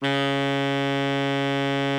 bari_sax_049.wav